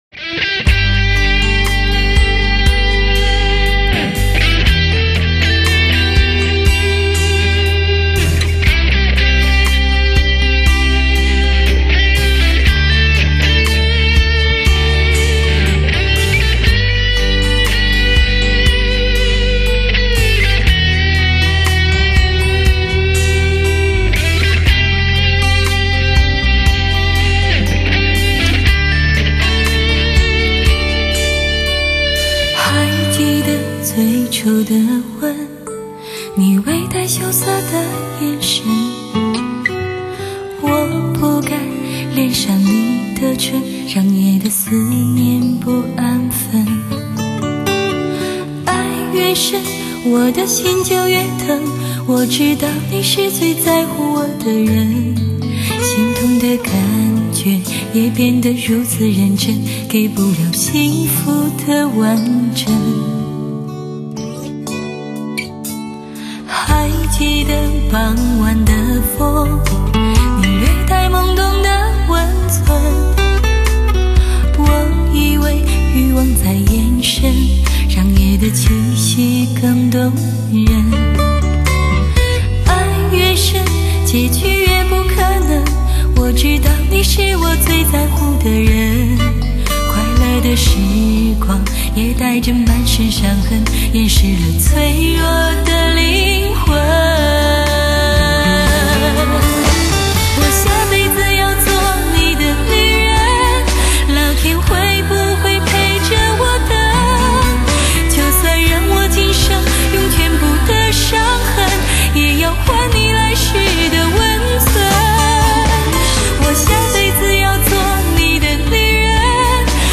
来自灵魂深处最令人感动的声音，让音乐带着心灵去疗伤……